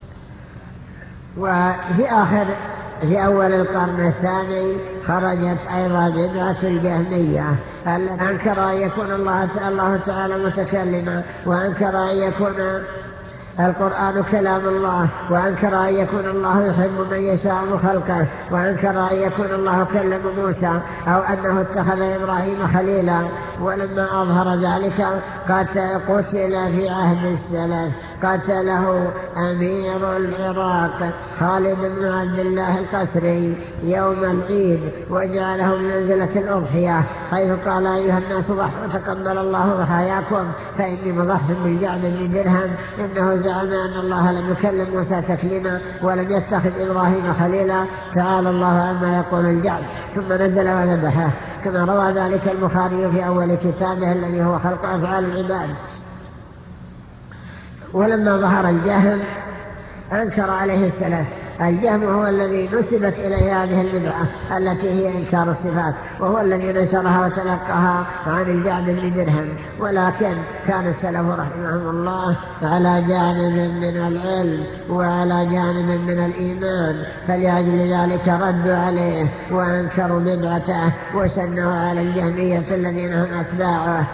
المكتبة الصوتية  تسجيلات - محاضرات ودروس  السلف الصالح بين العلم والإيمان